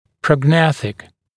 [ˌprɔg’næθɪk][ˌпрог’нэсик] прогнатический
prognathic.mp3